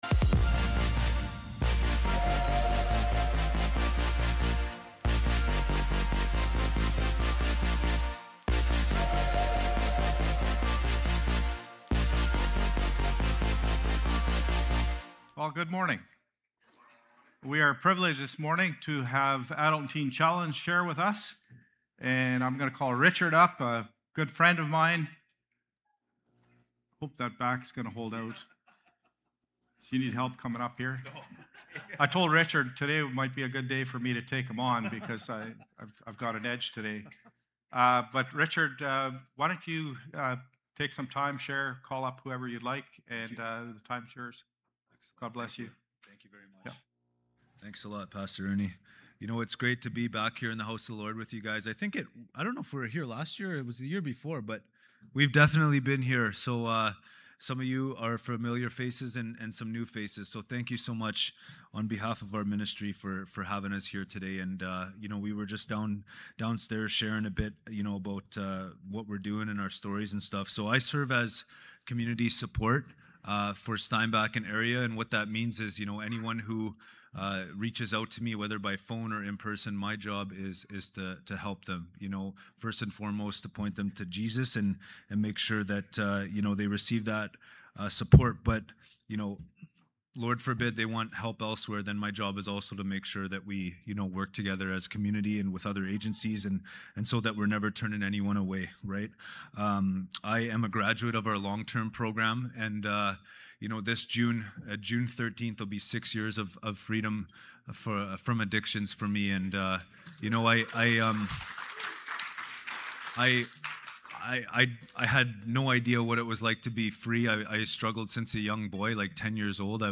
January-18-Service.mp3